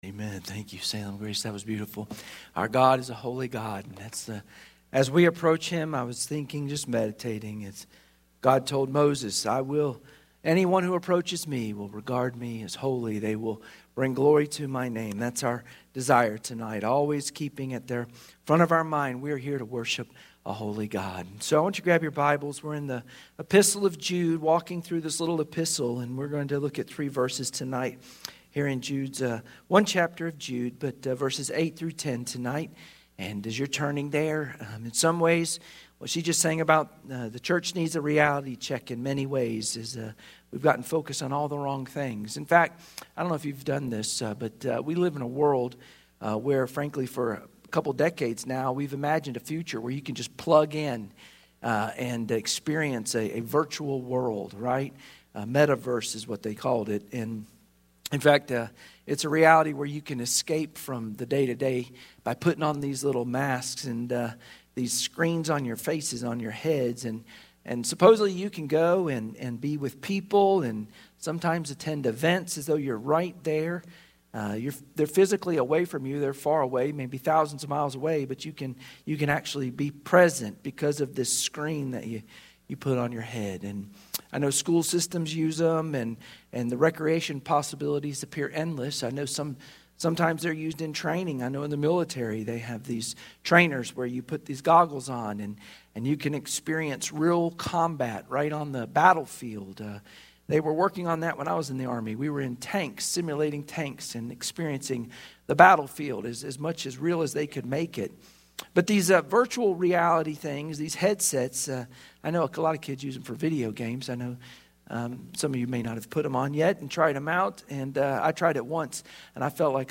Sunday Evening Service Passage: Jude 8-10 Service Type: Sunday Evening Worship Share this